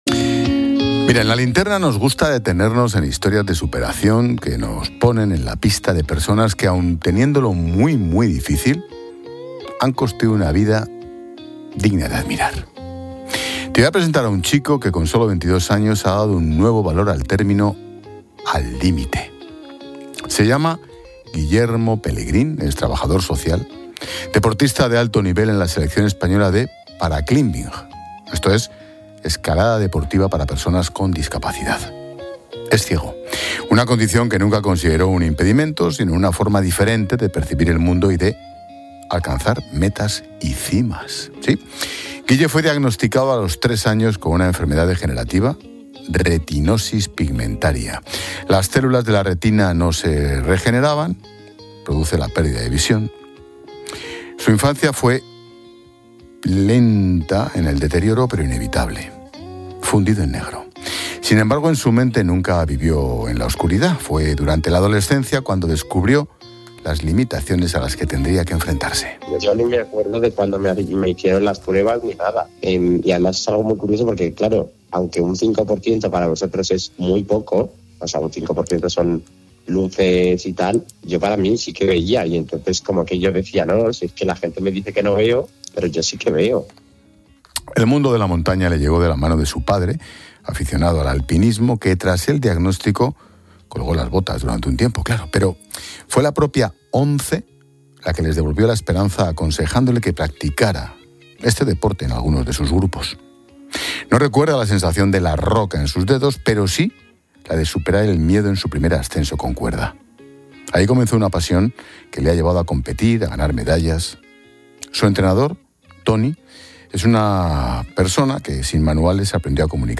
Expósito cuenta la historia